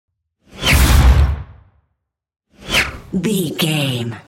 Airy whoosh pass by fast
Sound Effects
Fast
futuristic
pass by
sci fi